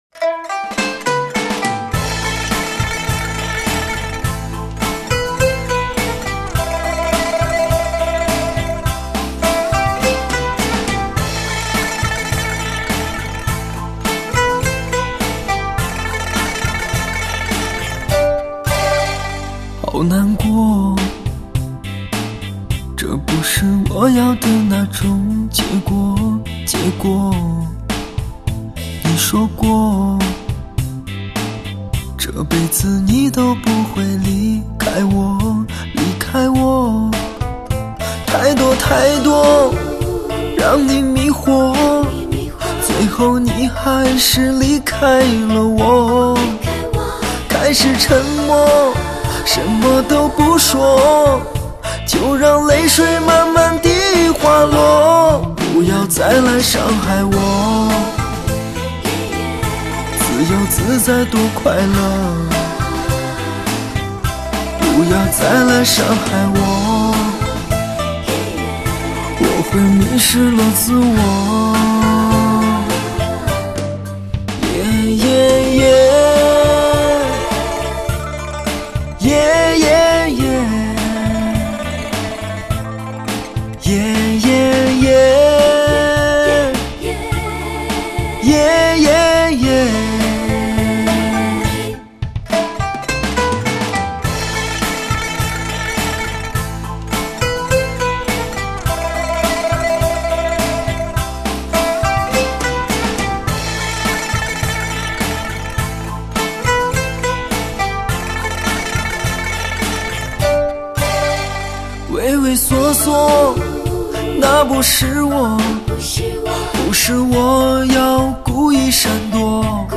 HD直刻无损高音质音源技术